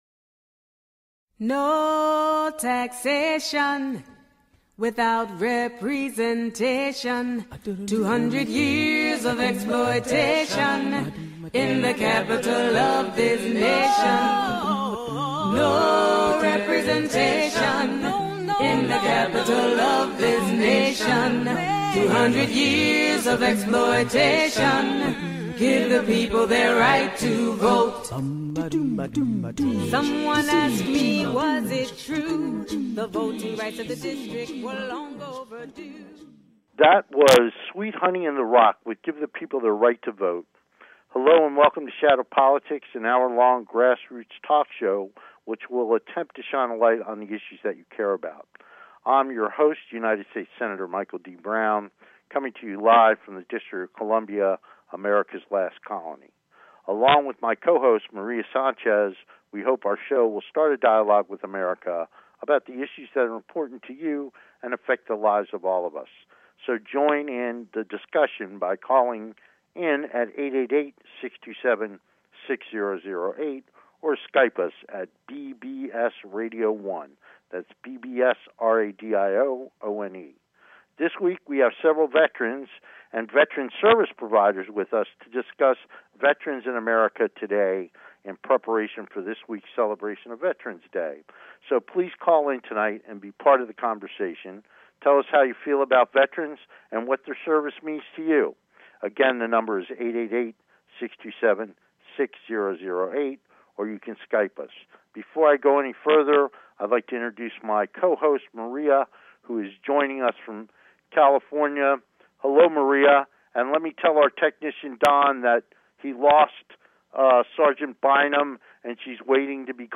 Talk Show Episode, Audio Podcast, Shadow Politics and Honoring Our Veterans on , show guests , about Veterans,Veterans Day, categorized as Politics & Government,Variety
Veterans Day is this Wednesday, November 11, 2015 and we will have three veterans on the show to talk about what it means to have served our country.